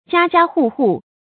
家家戶戶 注音： ㄐㄧㄚ ㄐㄧㄚ ㄏㄨˋ ㄏㄨˋ 讀音讀法： 意思解釋： 每家每戶。指所有的人家。